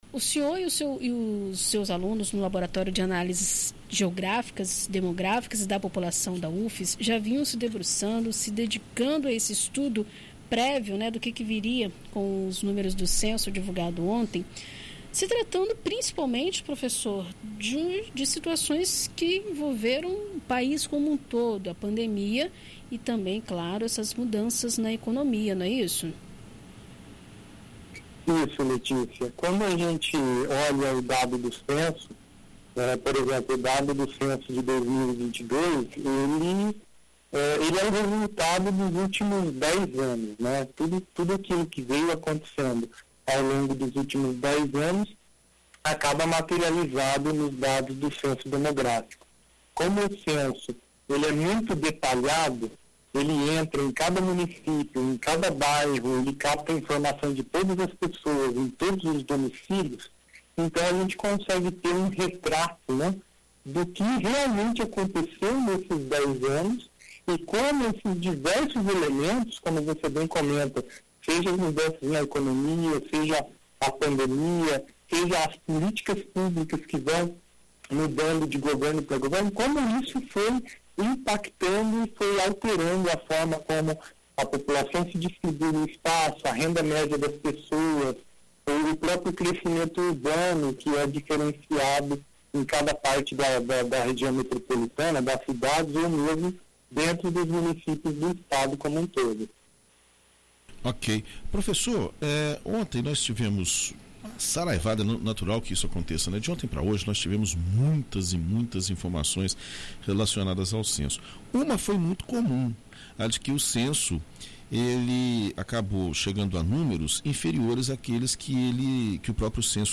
Em entrevista a BandNews FM Espírito Santo nesta quinta-feira (29)